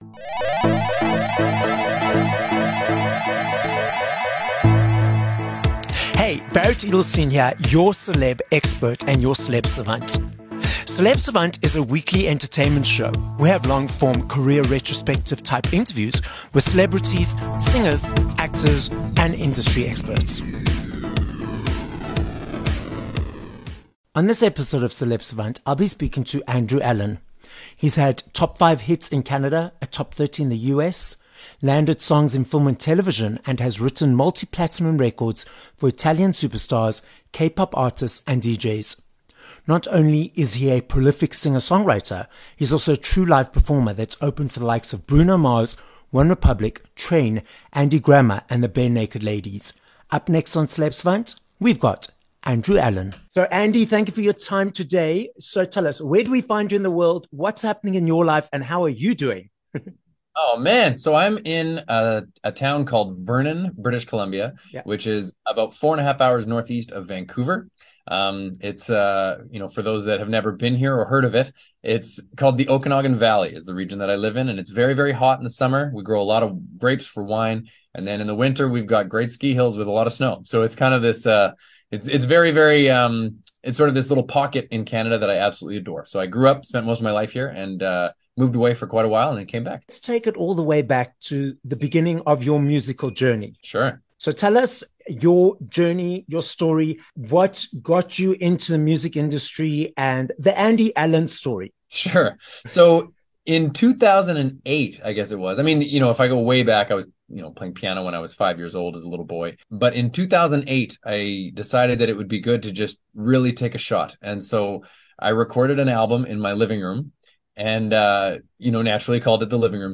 16 Jan Interview